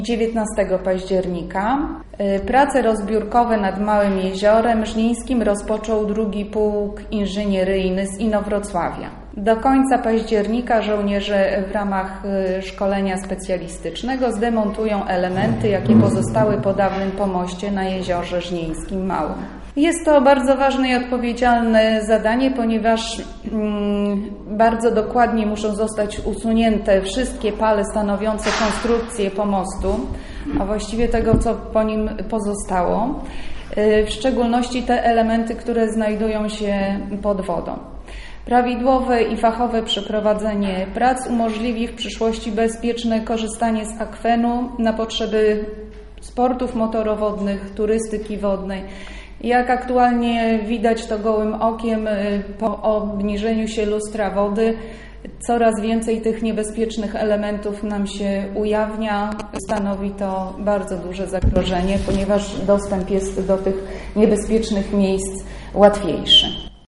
Prowadzi je 6 żołnierzy z 2. Inowrocławskiego Pułku Inżynieryjnego im. gen. Jakuba Jasińskiego - poinformowała na konferencji prasowej zastępca burmistrza Halina Rosiak